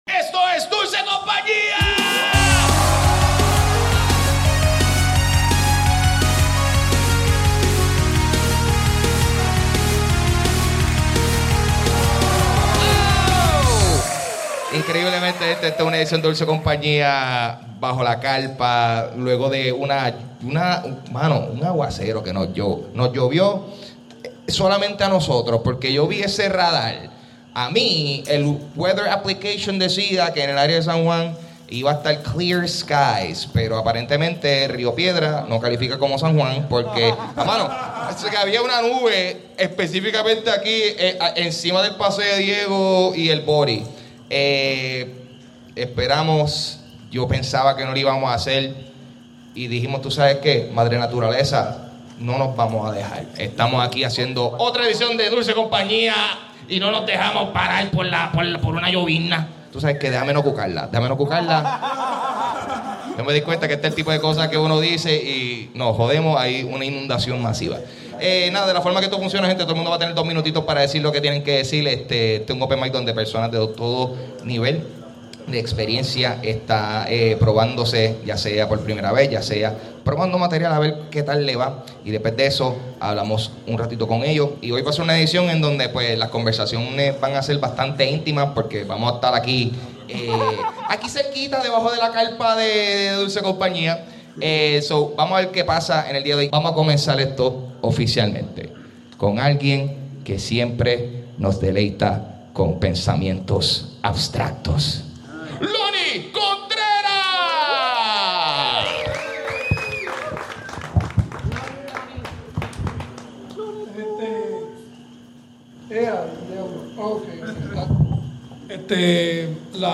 Hicimos el show bajo la protección de una carpa.